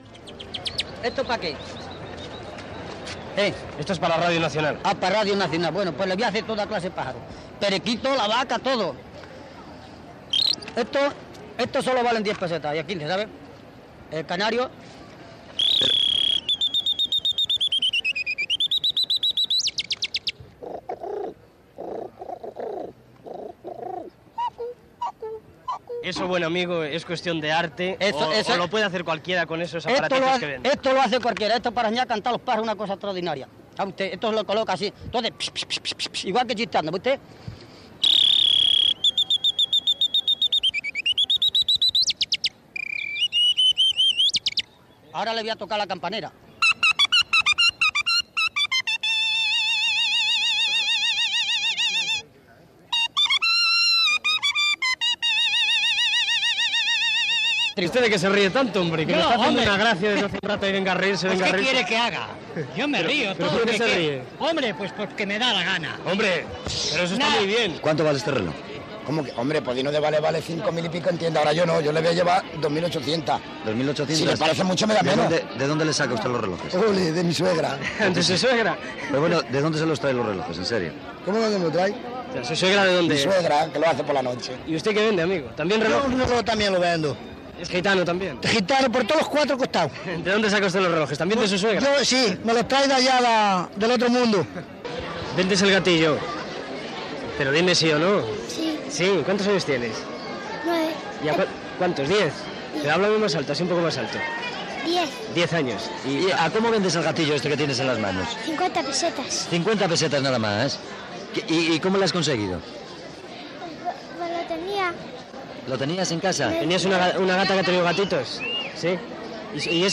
Reportatge fet al Rastro de Madrid, amb la intervenció de diversos venedors
Informatiu